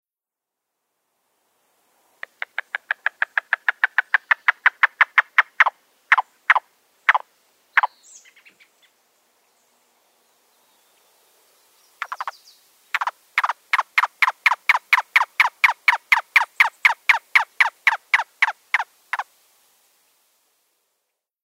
Yellow-billed Cuckoo
Bird call: Hollow-sounding single “kik” or “kow” call repeated multiple times.
Yellow-billed-cuckoo-call.mp3